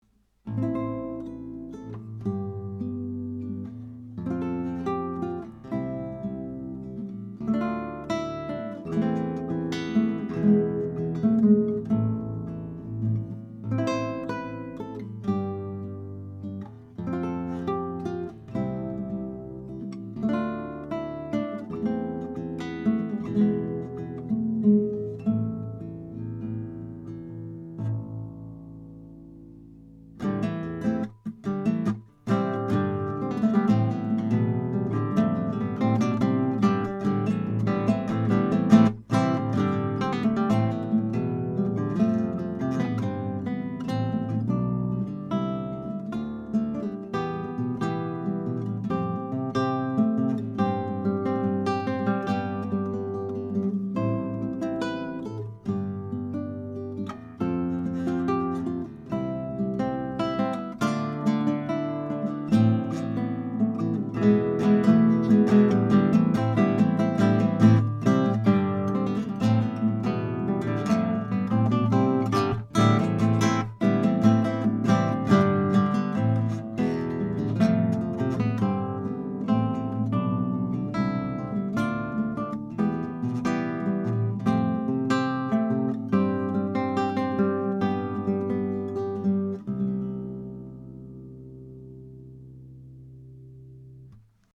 Tracked through a pair of Warm Audio WA12 preamps, into a Metric Halo ULN-8 interface, no compression, EQ or effects:
CLASSICAL HARP GUITAR